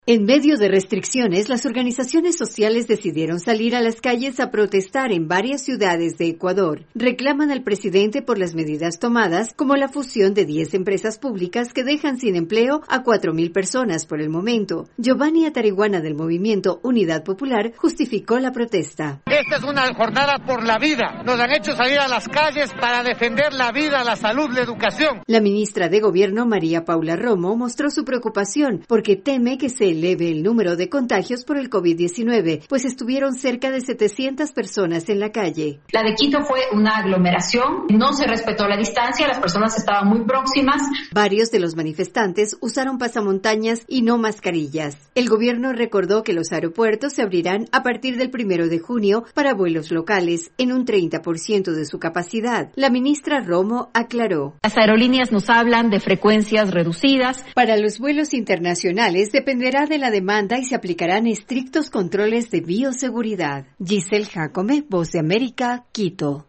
Ecuatorianos en Quito y otras ciudades protestan por la situación que enfrentan mientras el Gobierno anuncia la paulatina reanudación de vuelos. Desde Quito informa la corresponsal de la Voz de América